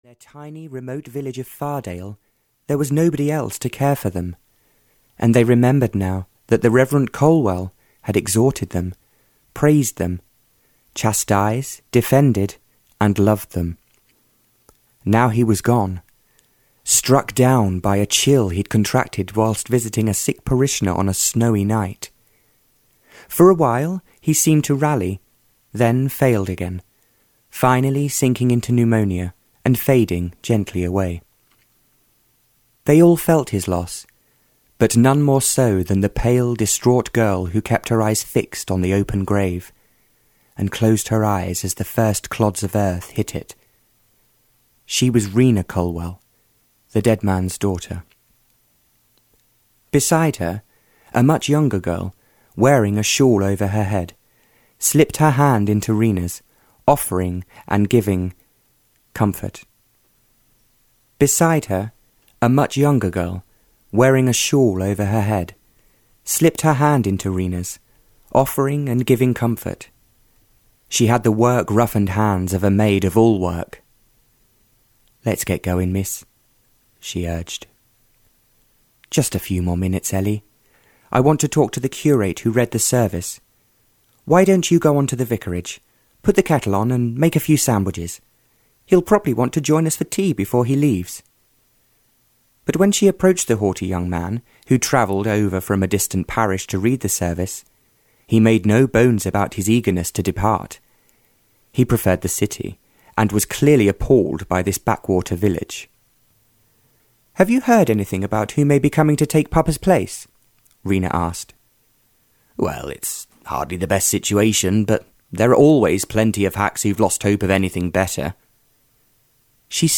The Cross of Love (Barbara Cartland’s Pink Collection 1) (EN) audiokniha
Ukázka z knihy